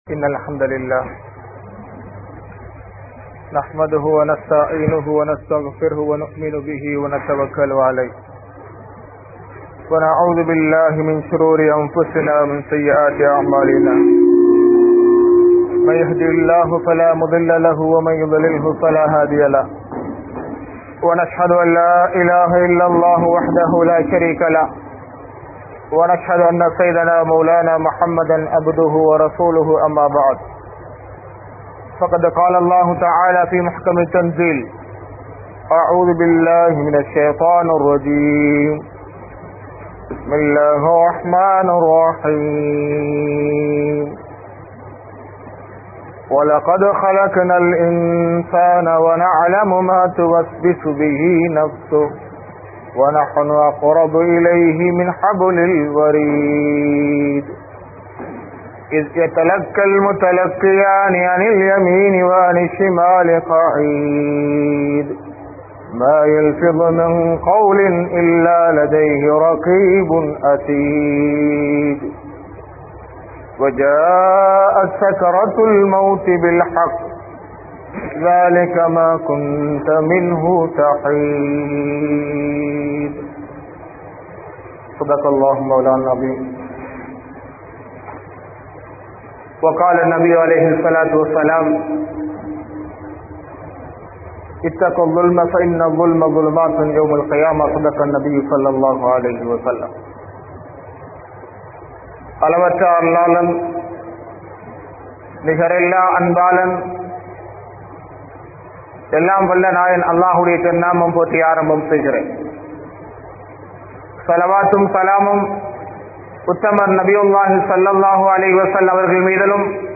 Eemaanai Paathuhaarungal (ஈமானை பாதுகாருங்கள்) | Audio Bayans | All Ceylon Muslim Youth Community | Addalaichenai